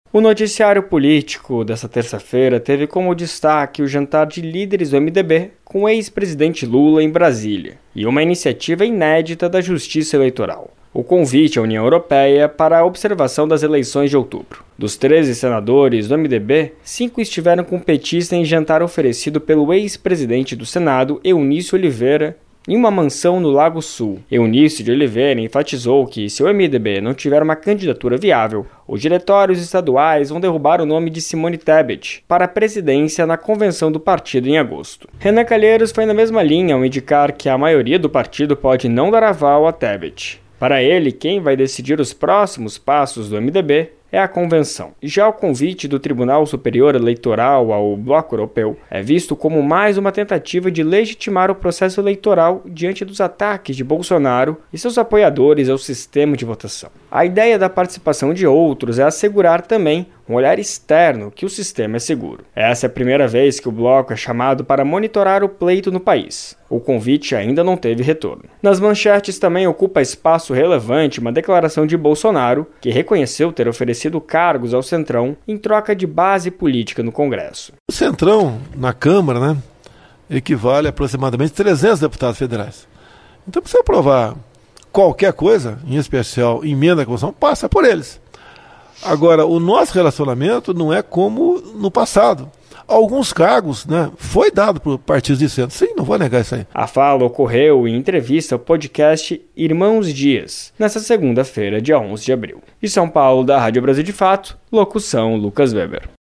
NOTICIÁRIO